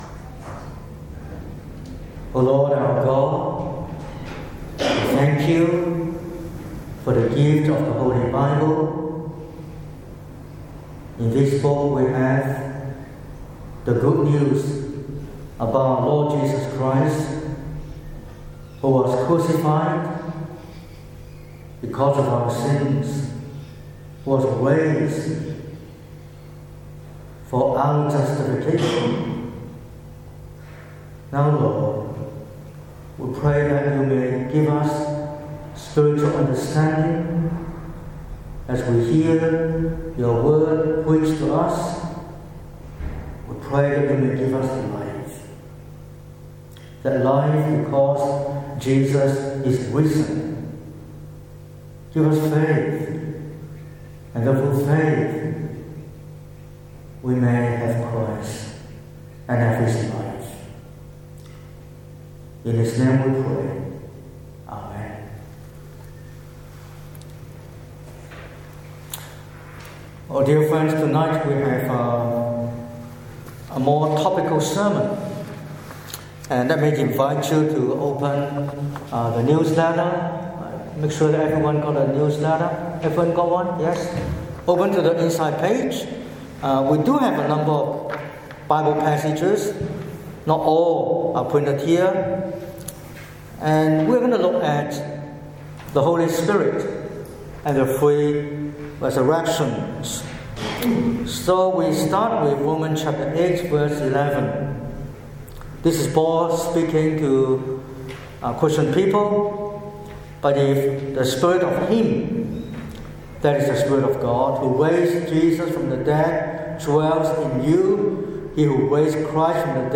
05/04/2026 – Easter Sunday Evening Service: The Holy Spirit and the Three Resurrections